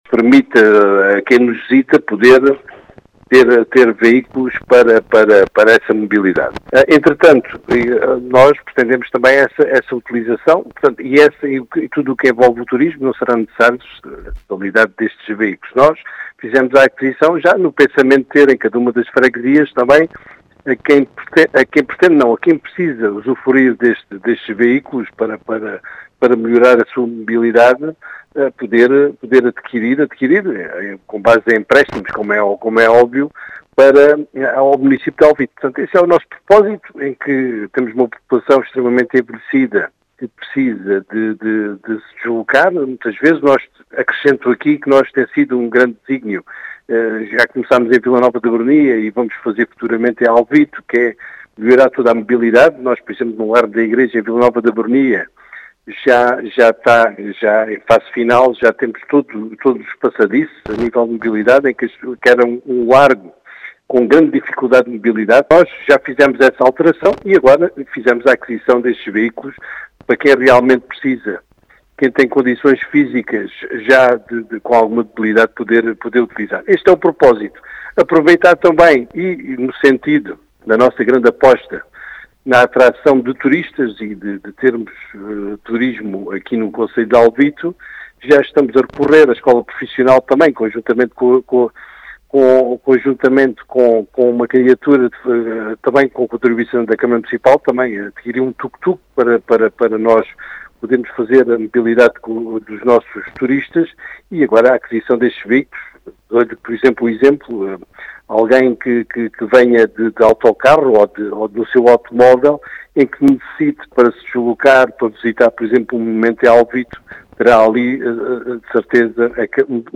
As explicações são do presidente da Câmara Municipal de Alvito, José Efigénio, que quer melhorar a mobilidade também, de quem visita o concelho.